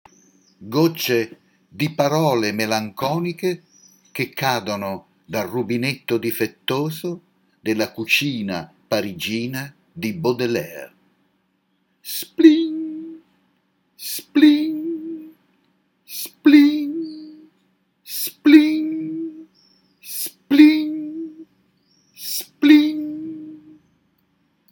Affermazione ripetuta in un pomeriggio caldissimo d'estate sotto un albero pieno di cicale.
Omaggio sonoro a Charles Baudelaire, Institut Français - Grenoble di Napoli, un mio omaggio sonoro al poeta dei Fiori del Male, in occasione della presentazione della plaquette Ciarle per Charles, alle ore 10:30, sabato 11 giugno 2022.